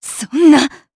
Xerah-Vox-Sur1_Madness_jp.wav